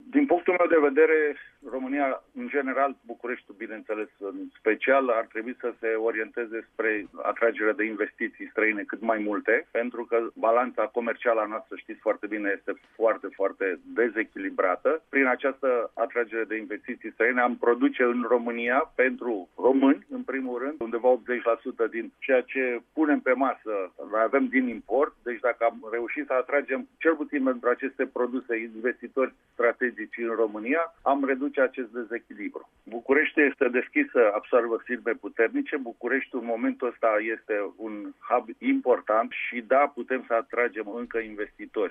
El a mai adăugat, la rubrica la Apel Matinal, de la RRA, că ţara noastră ar trebui să atragă cât mai multe investiţii străine pentru a echilibra balanţa comercială: